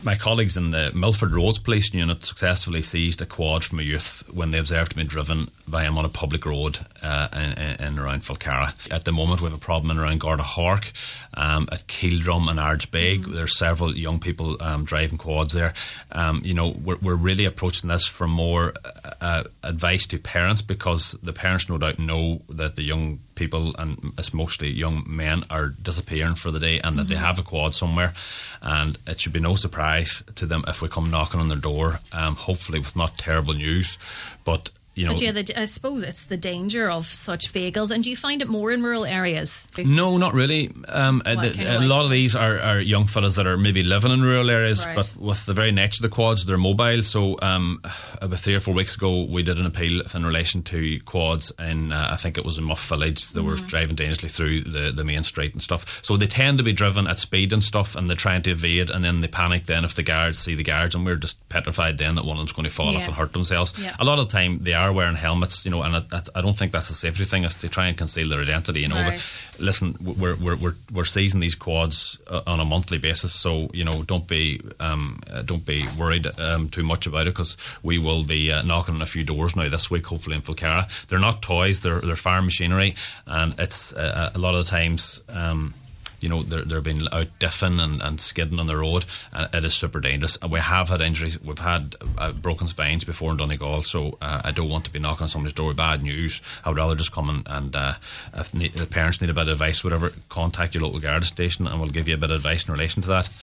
During the Community Garda slot on today’s Nine til Noon Show